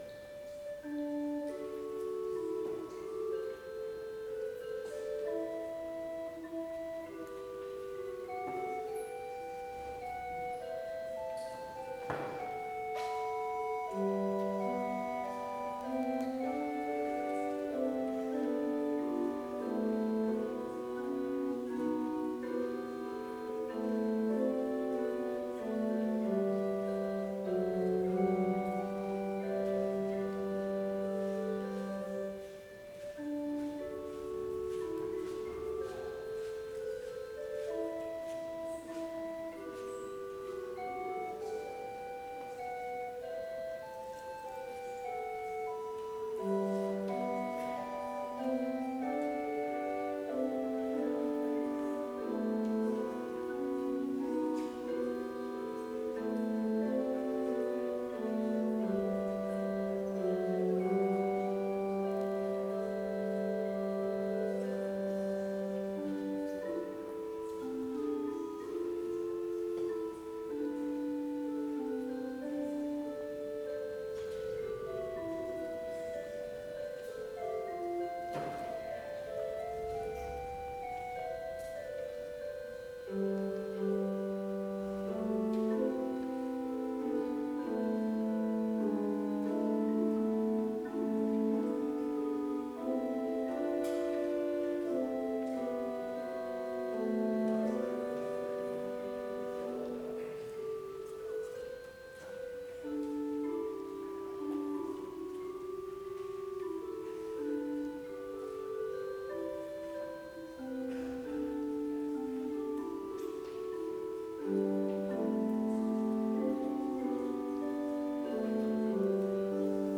Complete service audio for Vespers - Wednesday, August 27, 2025